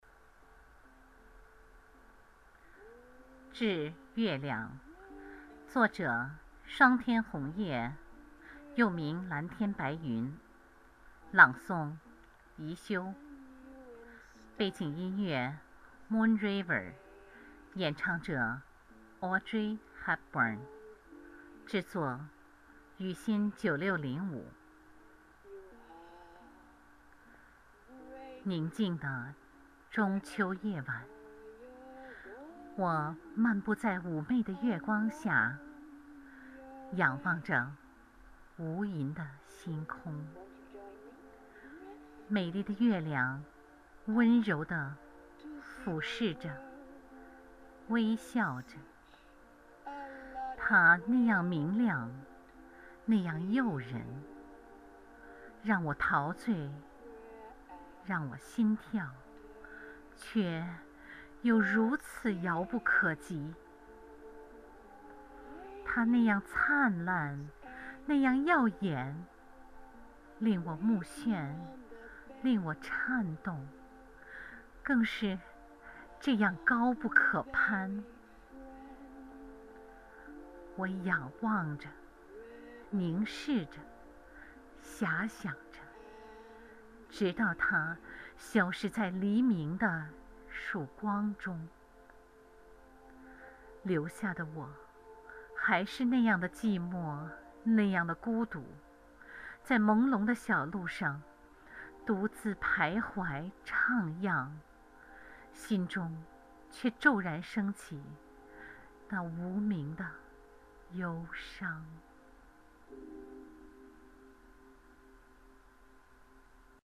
温柔, 内涵.
是同期录音。不会分轨。